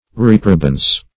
Reprobance \Rep"ro*bance\ (-bans), n.